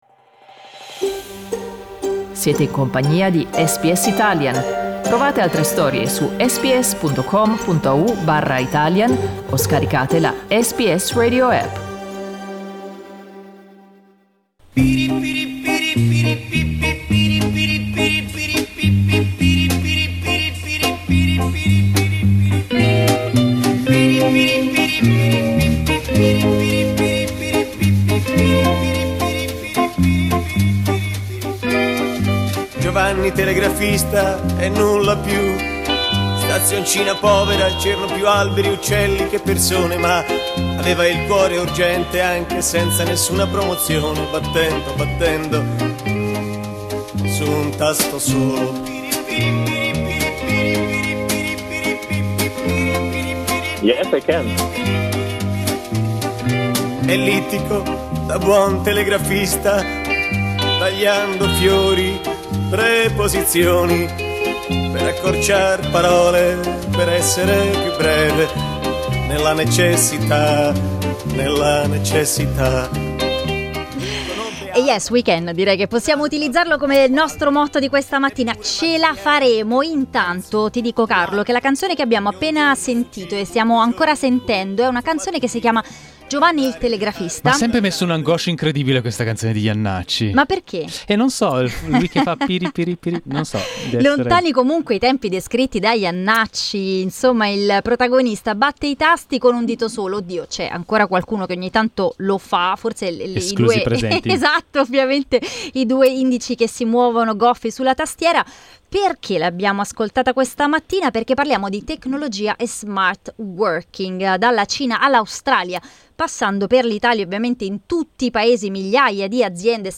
The current global coronavirus emergency has led an unprecedented number of people to experiment new ways of working from their own home. We talked to two IT experts and our audience about the challenges and the opportunities that may arise from this social experiment.